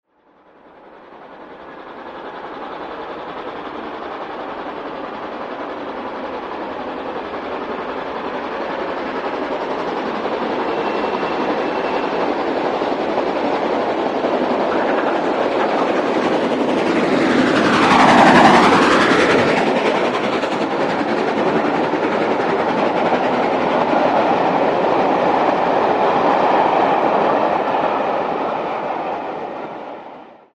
This third volume of tracks are all recordings of steam hauled trains on the main line heard from the lineside not all of which have appeared on the web site.
2. Another occasion when I had to resort to recording on a station was 7th September 2002 when the LMS Princess Royal Pacific 6201 'Princess Elizabeth' worked a charter train from Liverpool to Carlisle over the Settle Carlisle line.
As usual on these occasions there were plenty of other people about on the station but they weren't too distracting.
6201 had been stopped by signals at Settle Jc. to wait for the preceding service train, which I had travelled on to Settle, to reach Blea Moor before being allowed to continue and, having restarted, the loco makes a fine sound accelerating it's heavy train on the 1 in 100 gradient.